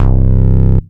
VEC1 Bass Long 18 C.wav